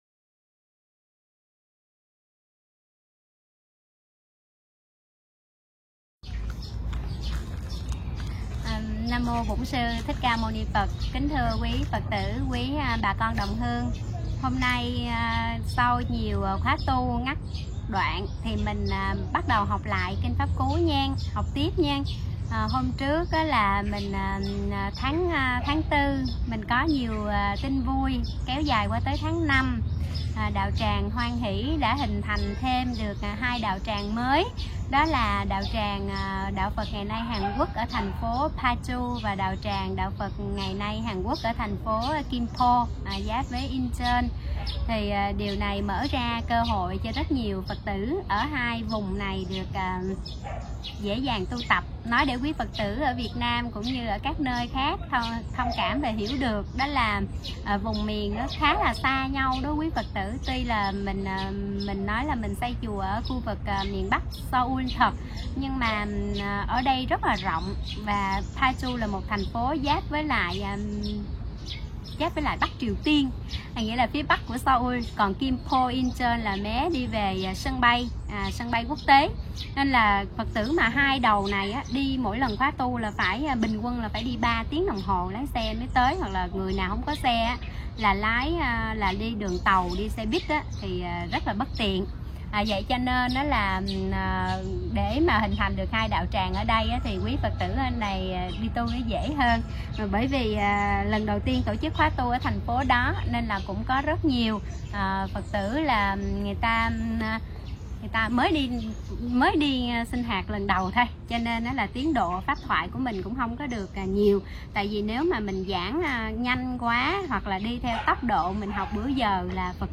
Tải mp3 thuyết pháp Sống bao lâu không bằng sống bao sâu